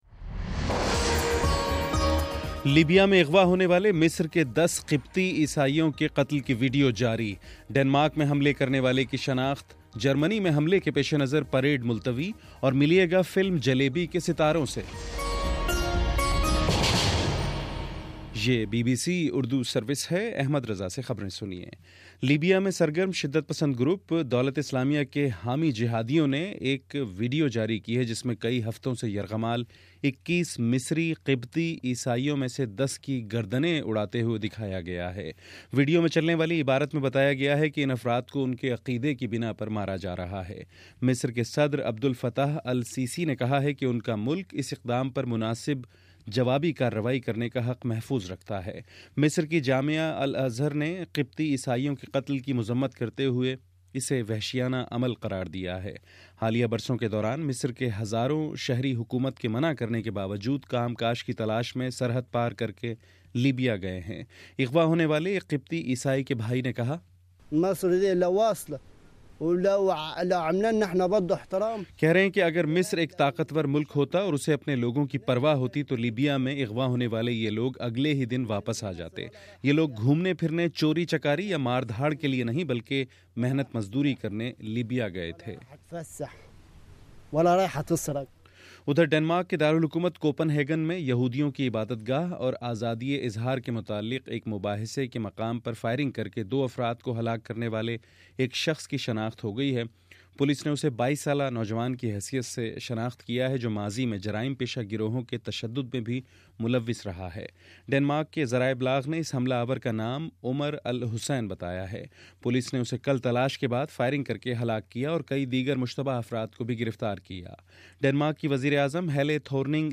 فروری 16 : صبح نو بجے کا نیوز بُلیٹن